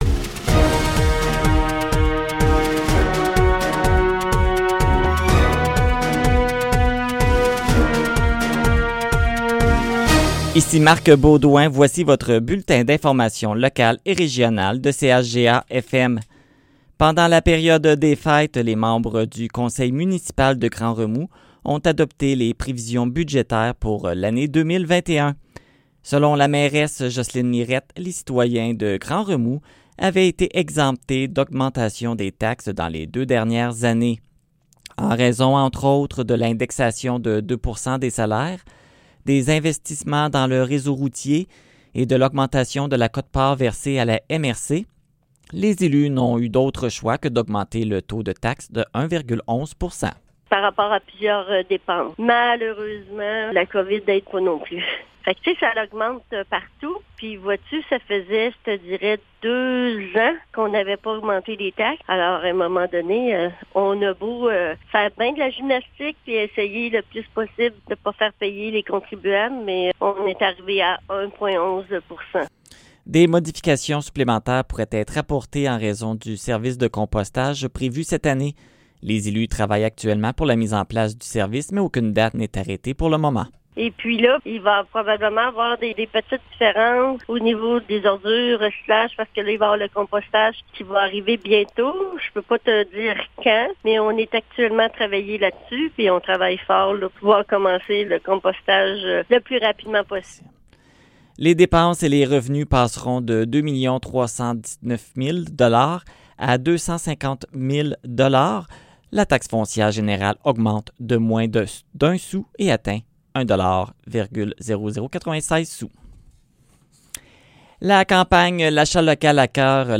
Nouvelles locales - 13 janvier 2021 - 15 h